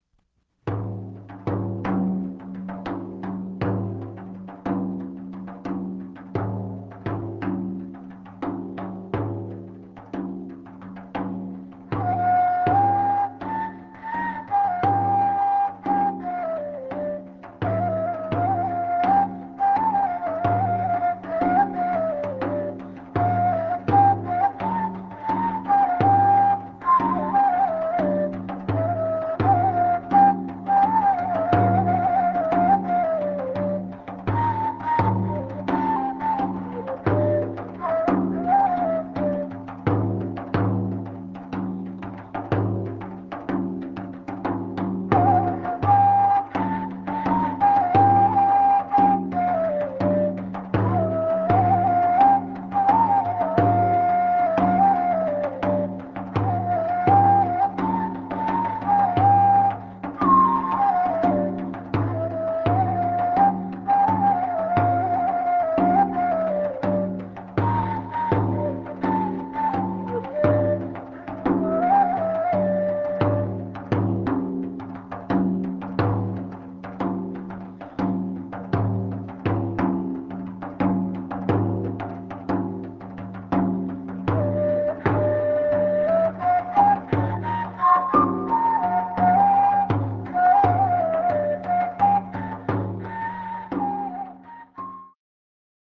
oud (Middle Eastern lute) and nay (cane flute)
Masmoudi